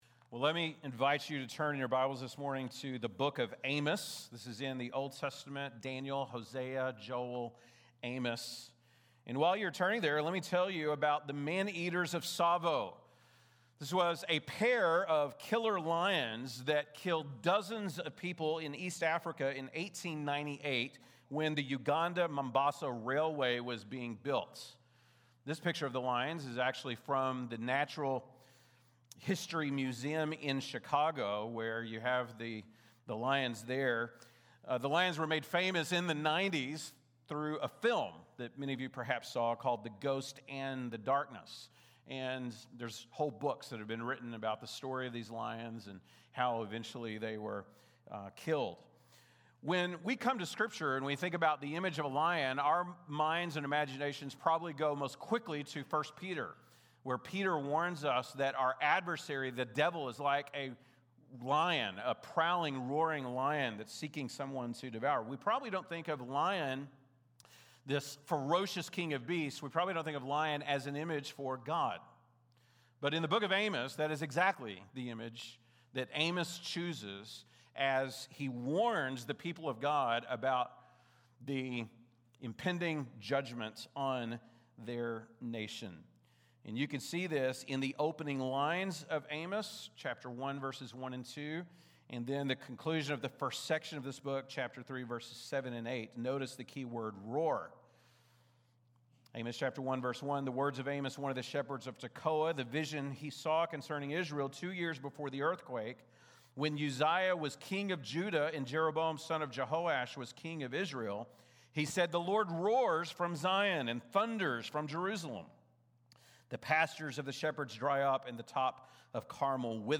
June 22, 2025 ( Sunday Morning )